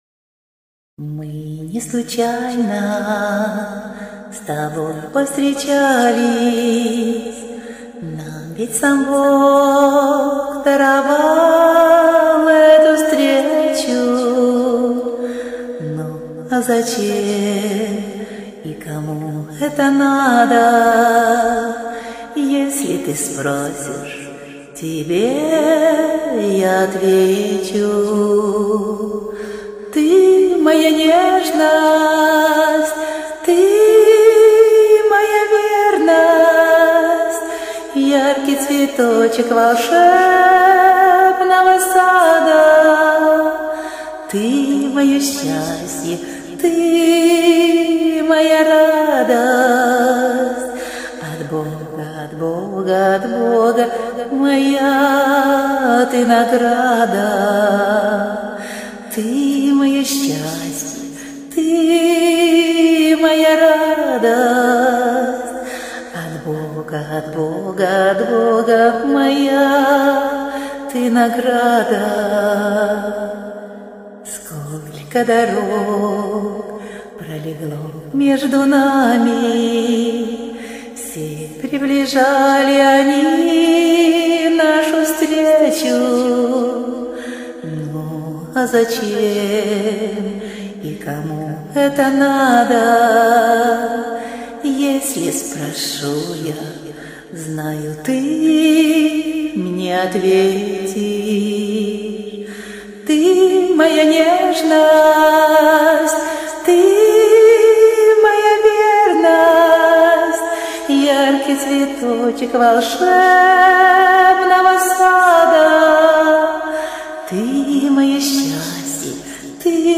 12 12 12 Спасибо за удовольствие!Ваш голос(хоть и не сильный),но звучит и хочется его слушать!Класс! clap clap clap tender flo26